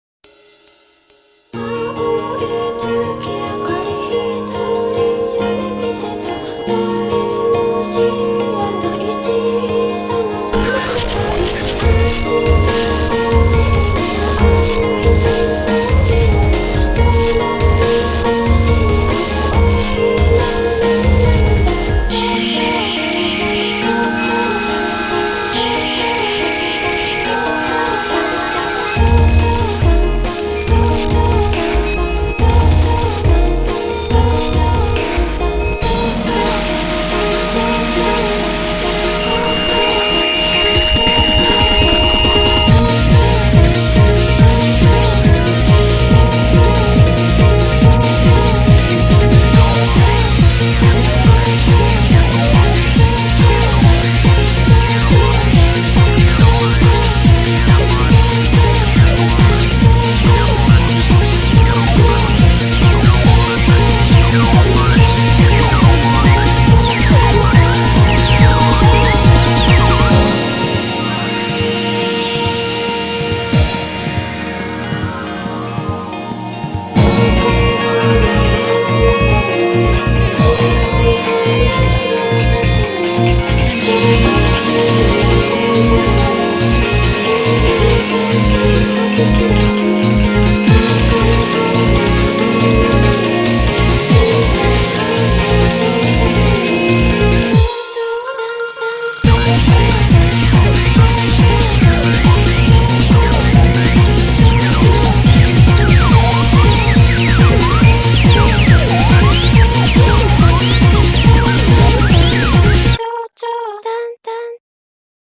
BPM70-140
Audio QualityPerfect (High Quality)
An odd sounding but fun song!